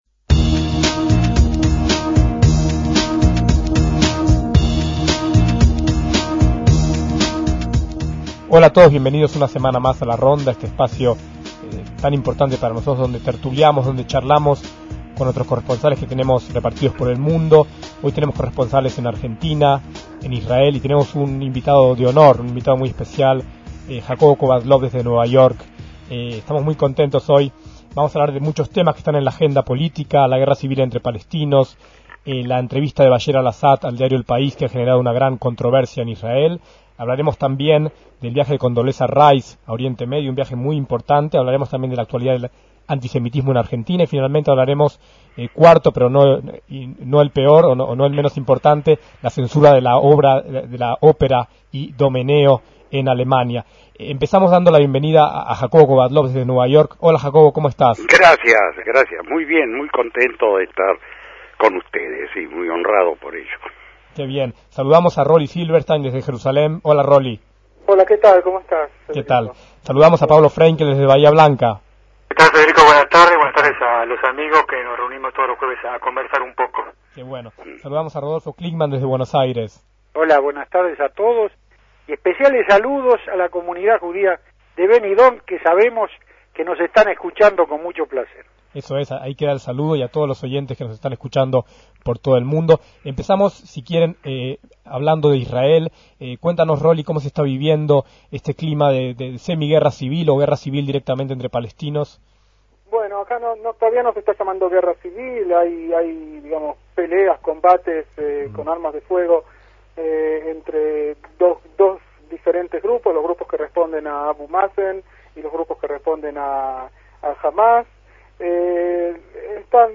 DECÍAMOS AYER (6/10/2006) - Varios de los corresponsales de Radio Sefarad debaten acerca de los enfrentamientos entre las facciones palestinas en Gaza, además de otros temas.